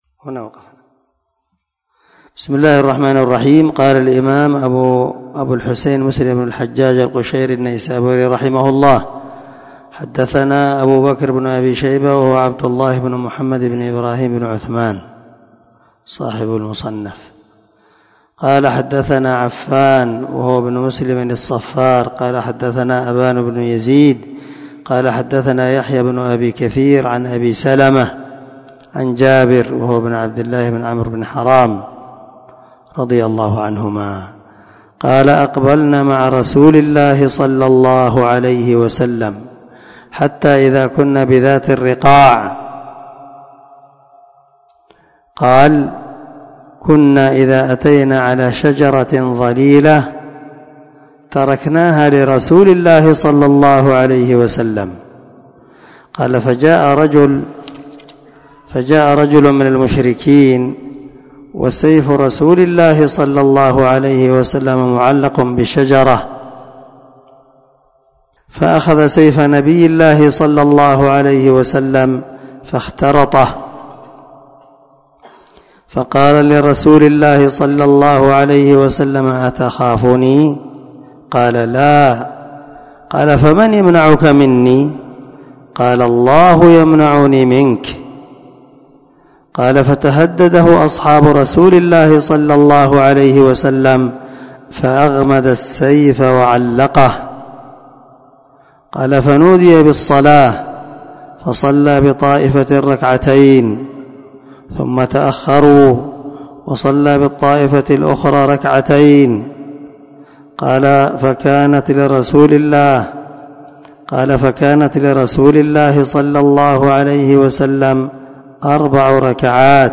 سلسلة_الدروس_العلمية
دار الحديث- المَحاوِلة- الصبيحة.